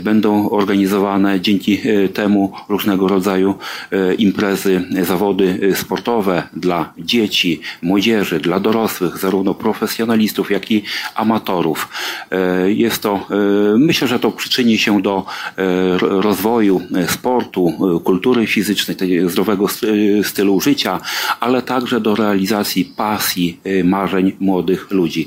Mówił marszałek Marek Malinowski.